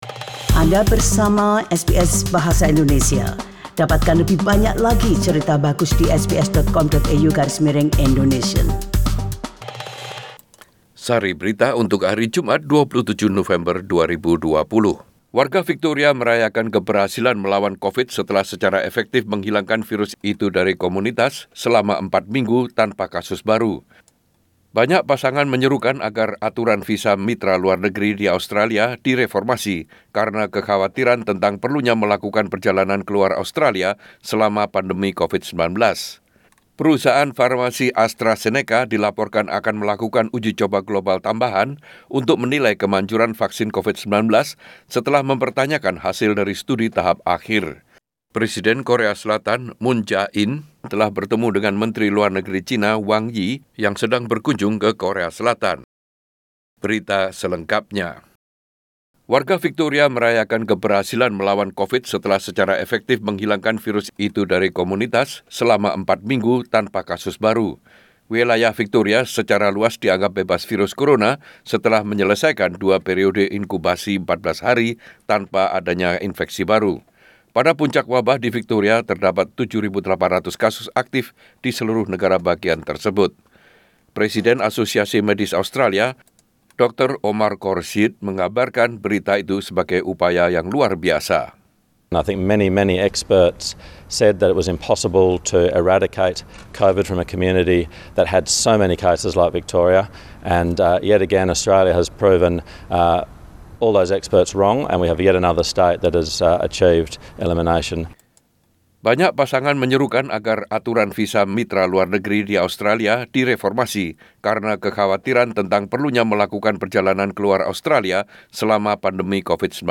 SBS Radio News in Bahasa Indonesia - 27 November 2020